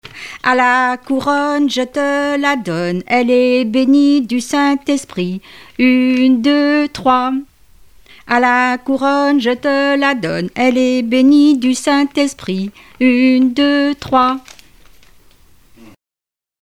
Enfantines - rondes et jeux
enfantine : comptine
Répertoire de chansons populaires et traditionnelles
Pièce musicale inédite